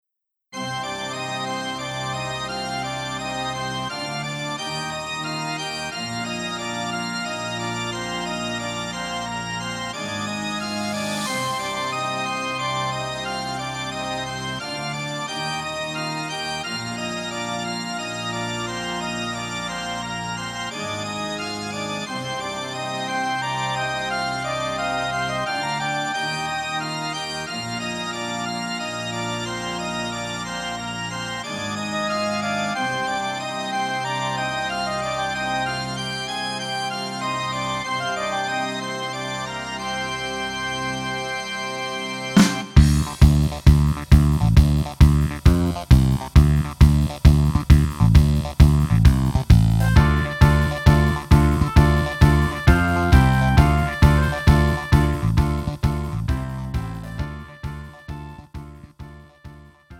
음정 원키 2:24
장르 가요 구분 Pro MR